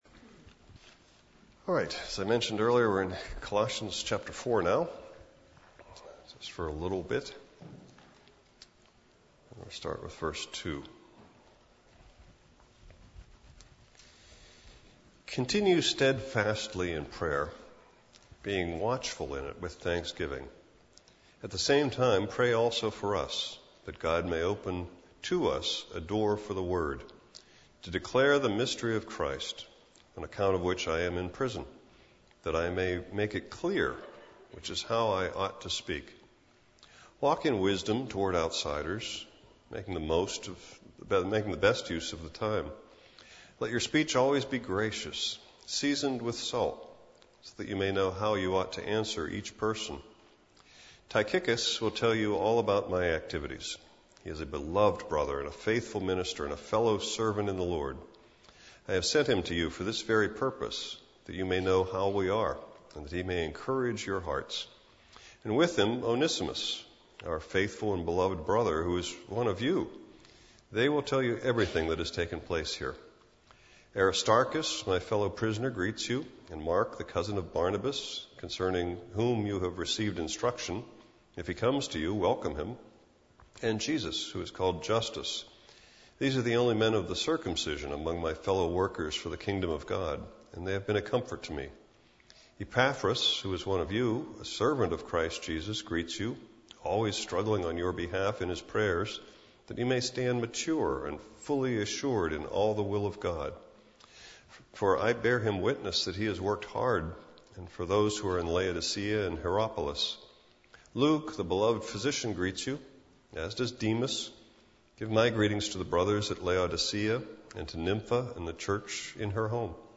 Interesting to me that our Senior Saints study today also took us into Hebrews which is where I am planning on going next for our sermons.